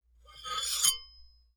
Metal_88.wav